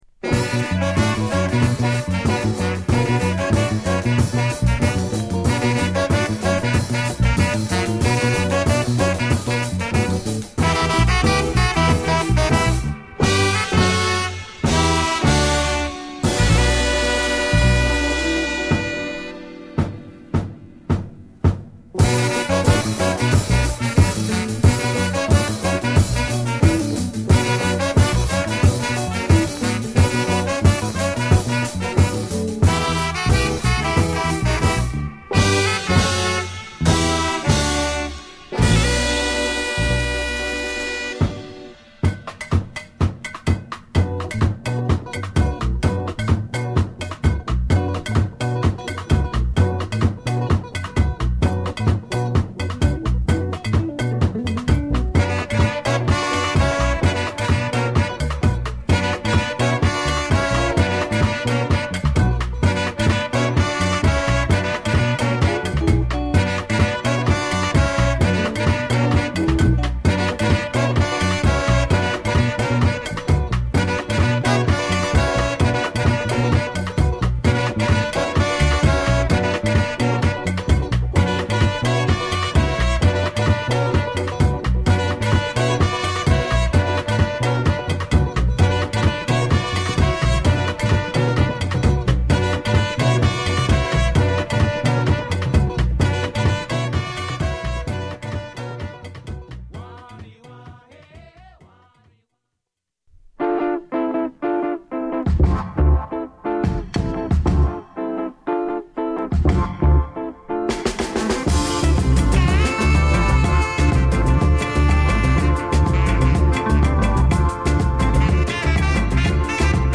「黒より黒い」音がここにある。